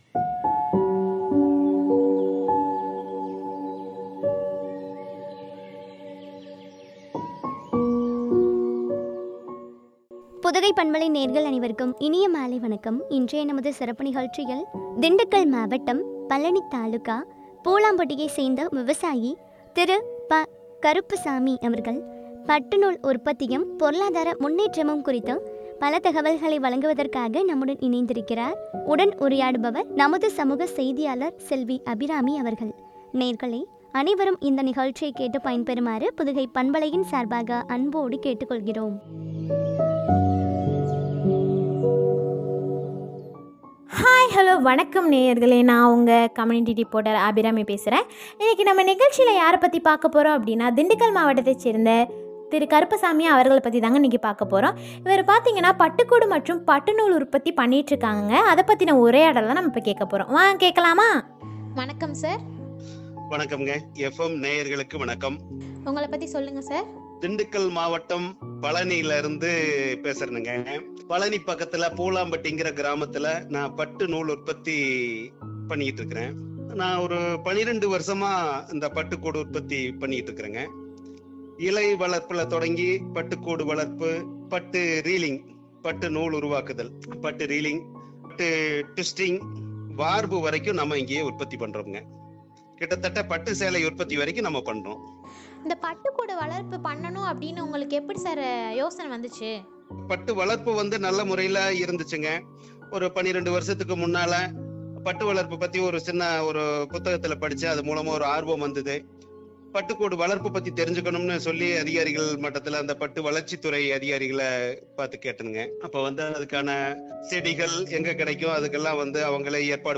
பொருளாதார முன்னேற்றமும் பற்றிய உரையாடல்.